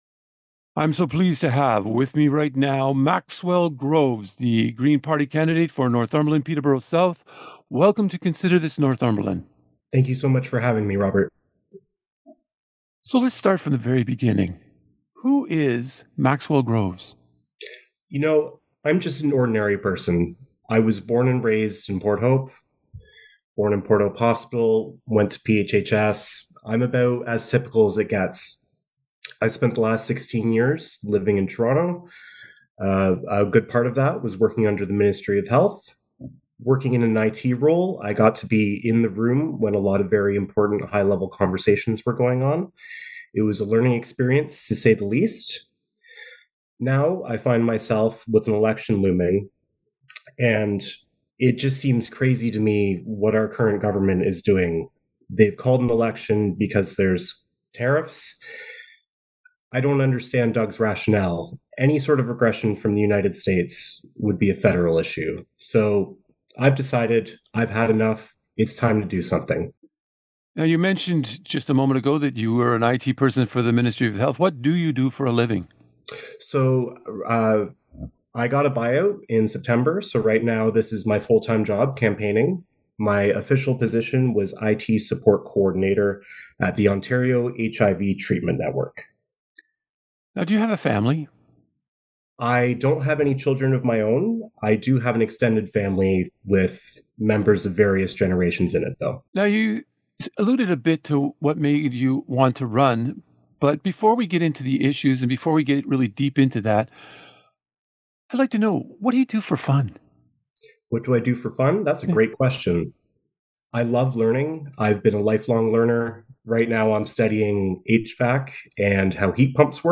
In a series of extensive, in-depth interviews with the major parties, Consider This Northumberland digs into key issues to help local voters cast their ballots on February 27.